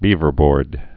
(bēvər-bôrd)